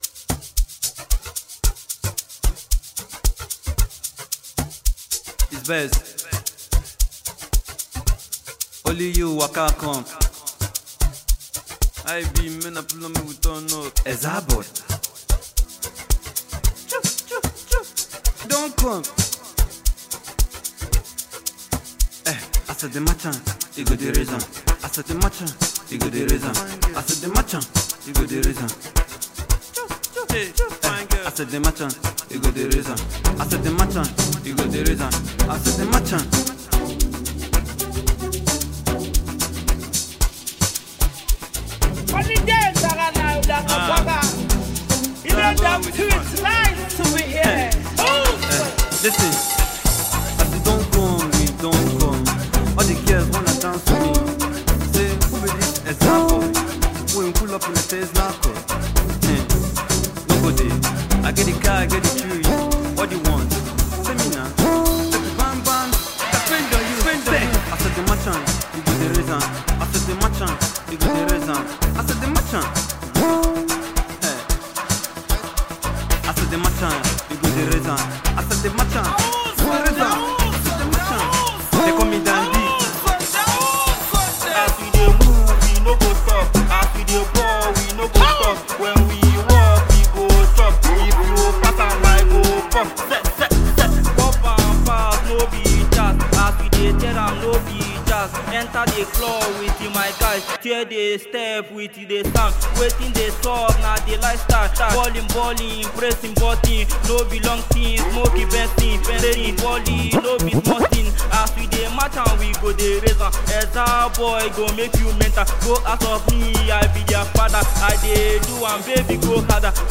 is an energetic Afrobeat/Afro-fusion anthem